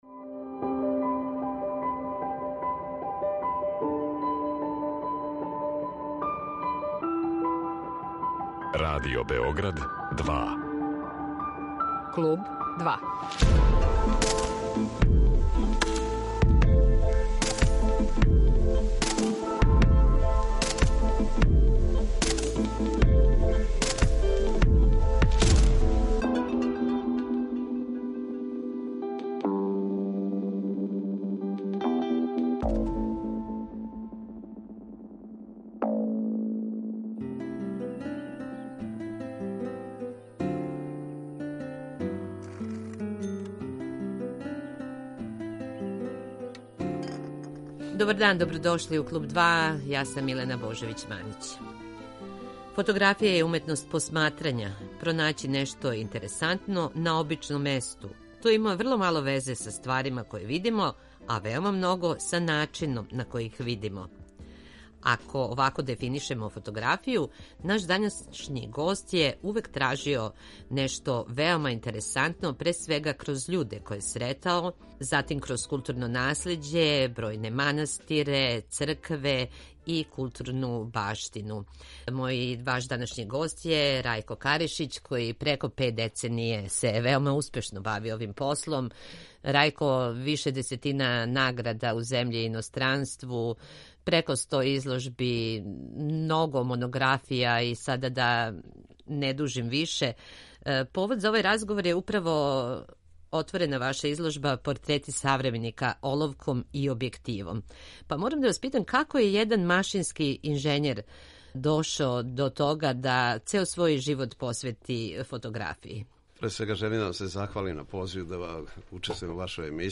Гост емисије је фотограф